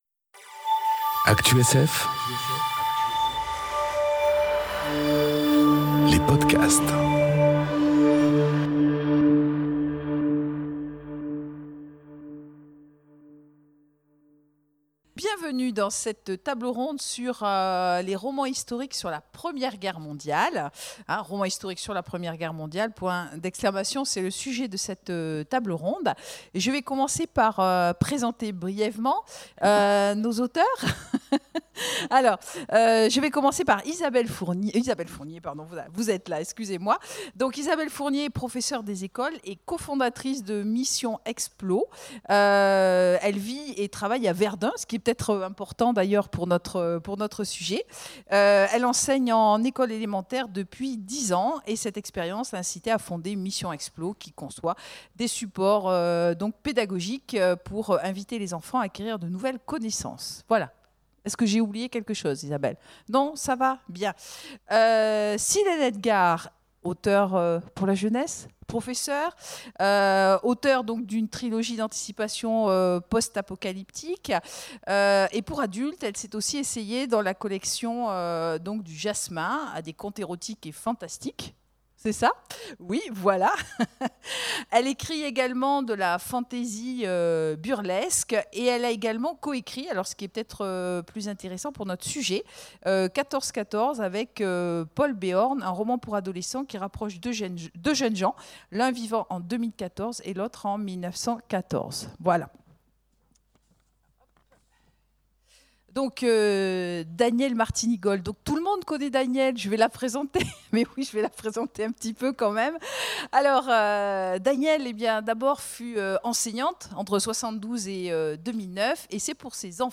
Conférence Romans historiques sur la Première Guerre Mondiale ! enregistrée aux Imaginales 2018